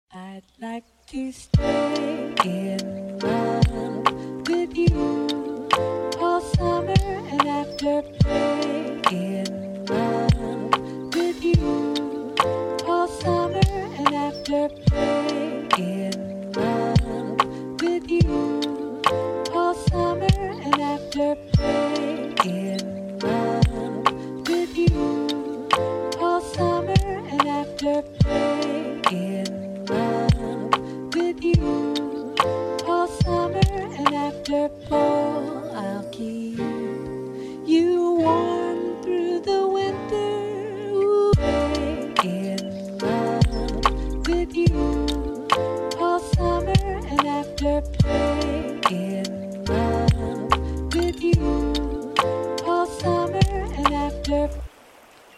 Café Pluie : Sons Pour Focus